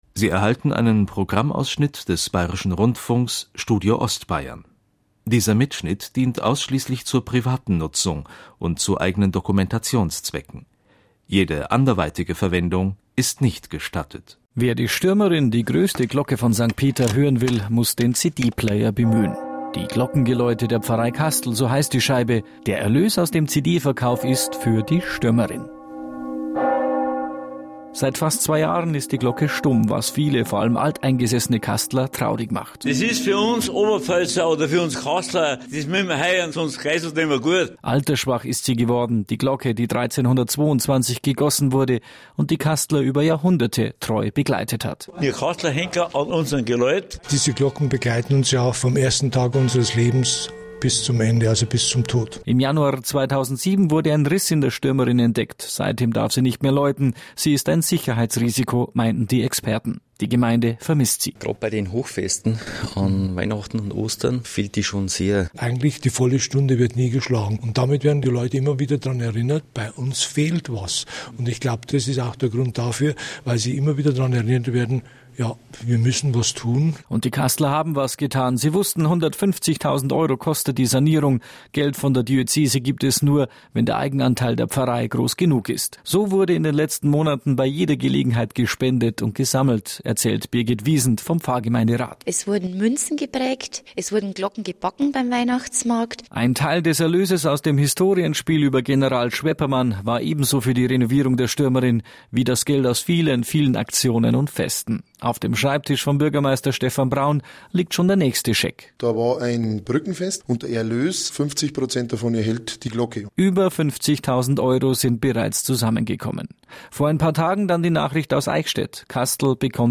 Der bayerische Rundfunk war zu unserer Freude ein weiteres Mal in Kastl zu Gast, um einen Hörfunkbeitrag zu erstellen.